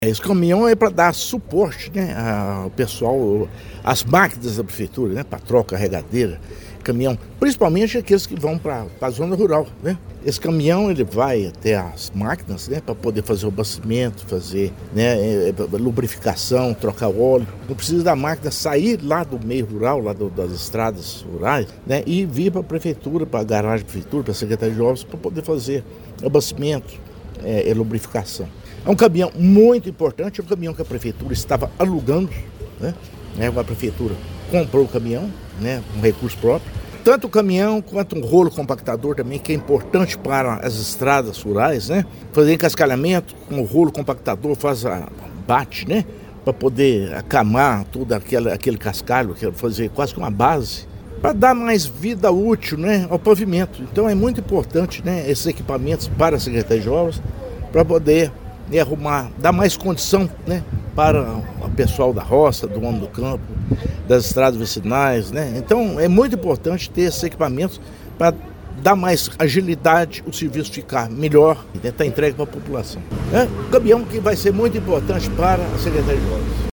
O prefeito Inácio Franco destacou a importância do caminhão como suporte direto às equipes que atuam no campo, garantindo mais agilidade e melhores condições de trabalho: